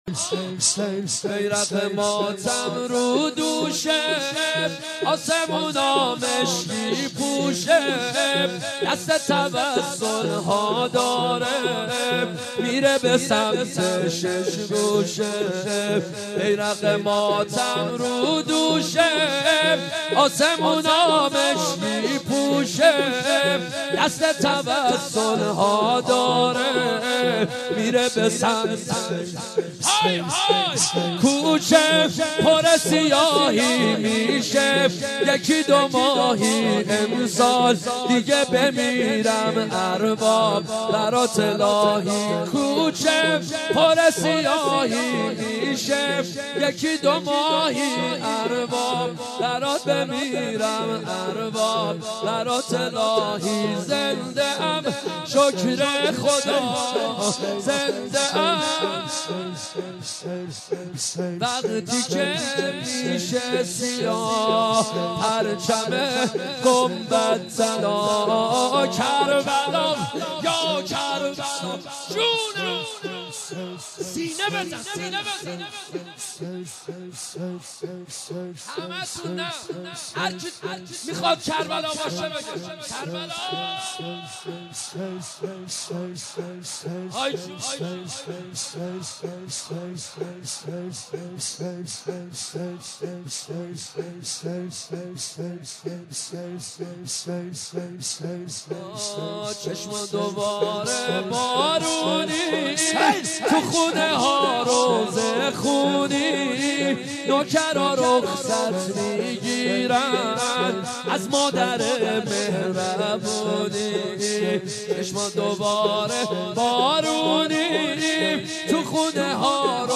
مناسبت : شب اول محرم
قالب : شور